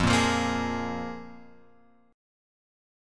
Fmbb5 chord {1 1 3 3 x 4} chord